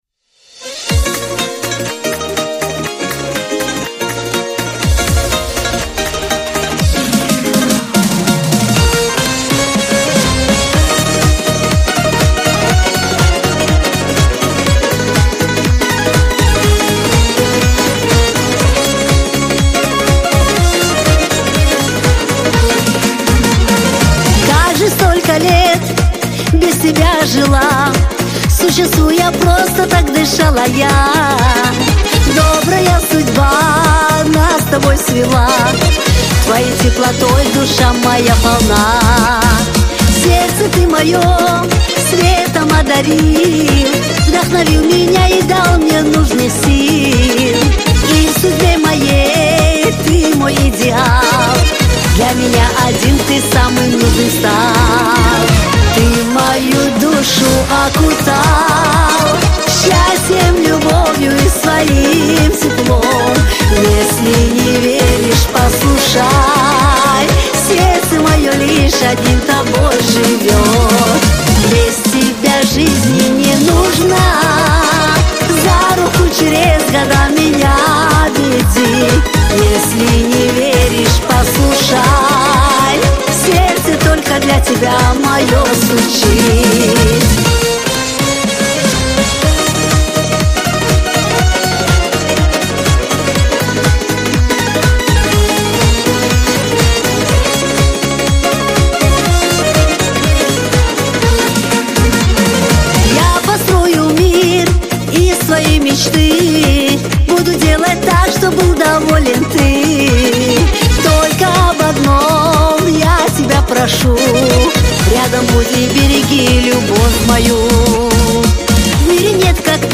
Скачать музыку / Музон / Кавказская музыка 2024